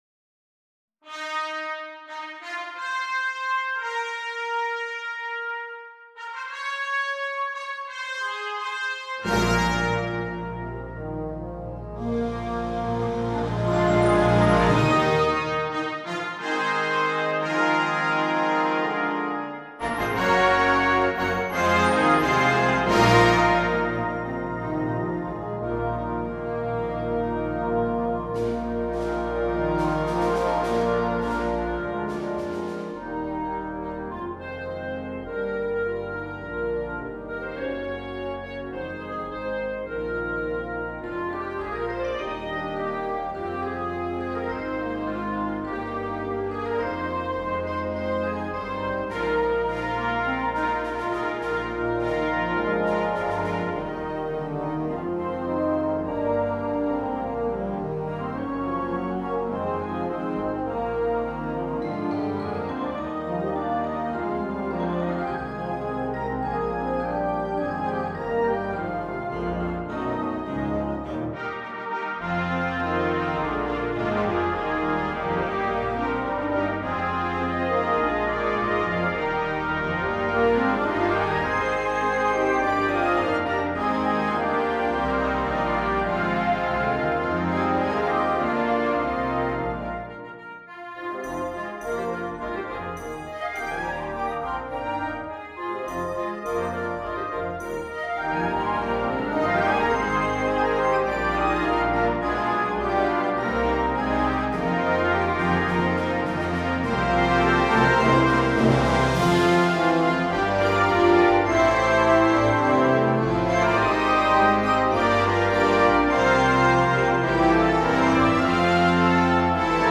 2. Harmonie
Volledige band
zonder solo-instrument
originele compositie